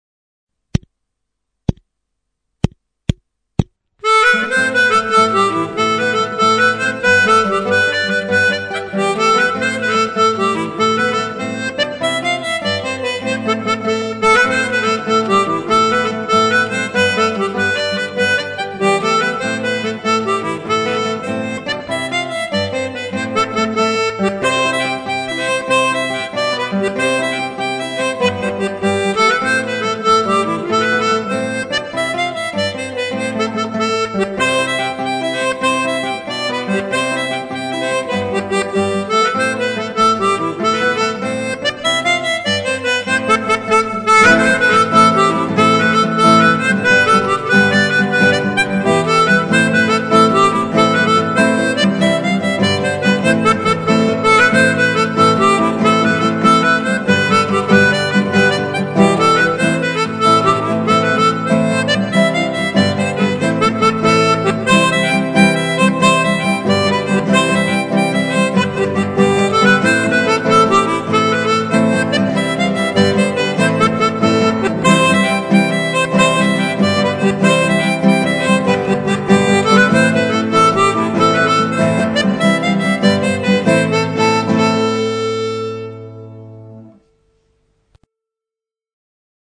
The Gaelic Club - Trad irlandais - Harmonica
The Gaelic Club - Trad irlandais
Voici la démo de ce trad irlandais réenregistré ce matin, facile à jouer, en Do, très bien pour travailler la rapidité.
L'harmonica va super bien sur le folk comme ça.